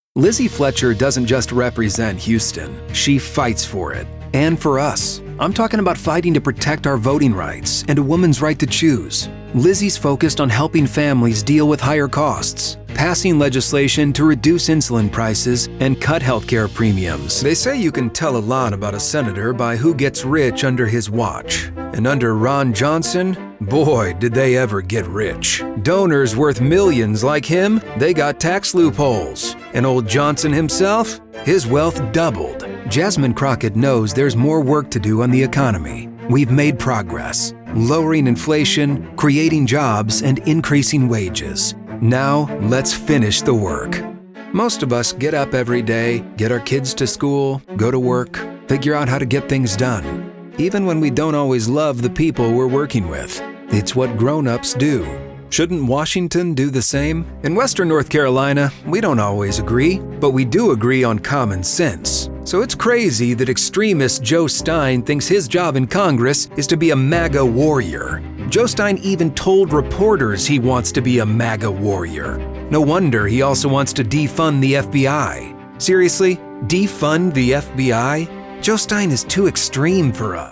chicago : voiceover : commercial : men